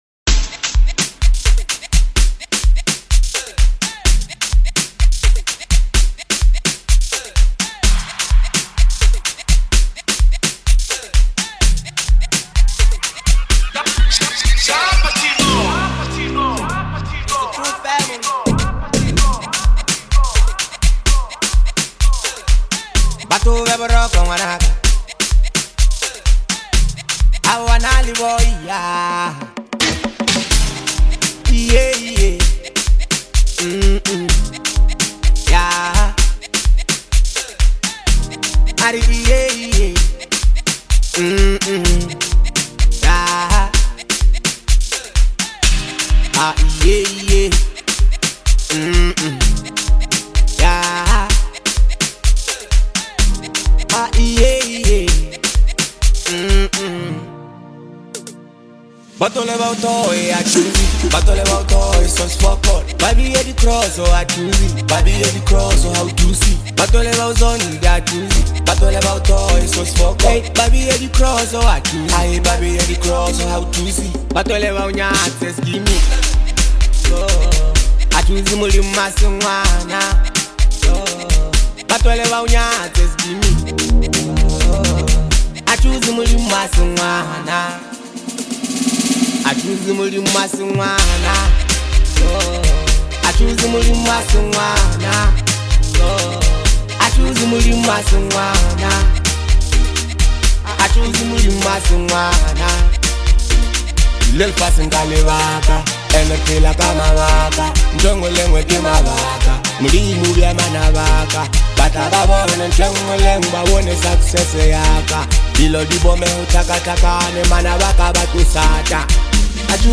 Where to Experience More Inspired Gospel Music